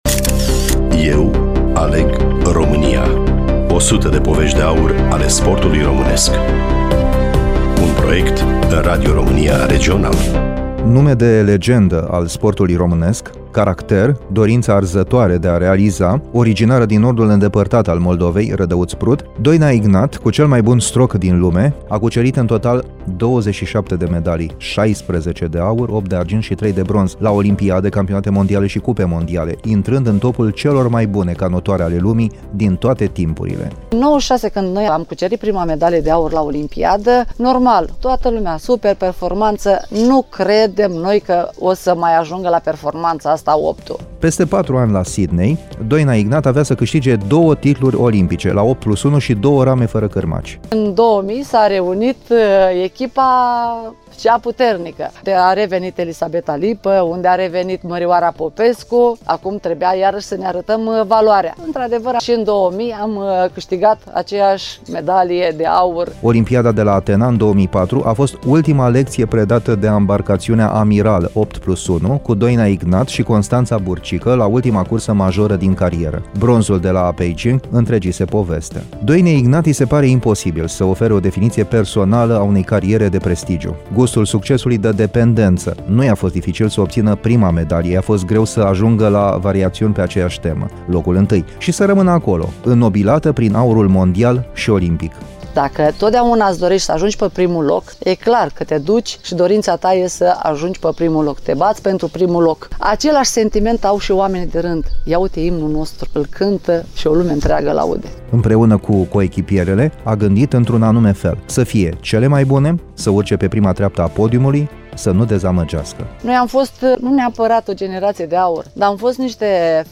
Studioul Radio Romania Iasi